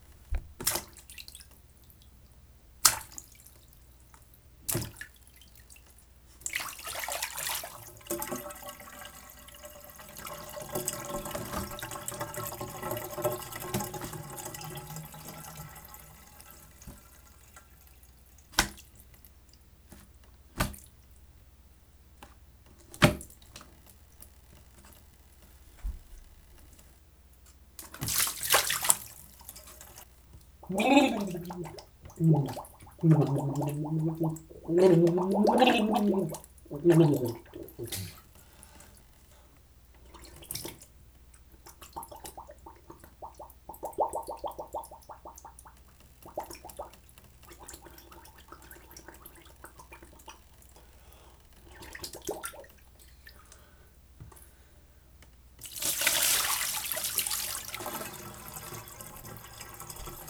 waterrecordings.wav